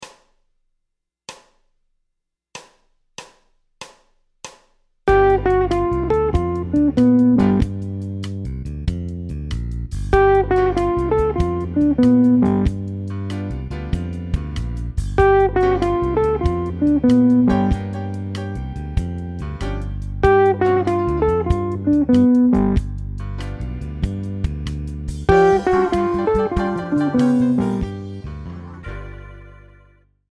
Pict domPhrases sur accords de dominante non altérés
G7 Motif de descente chromatique avant de rejoindre la note diatonique supérieure. .Encapsulation de la Septième mineure  en fin de phrase.